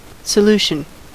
Ääntäminen
US : IPA : [səˈluː.ʃən]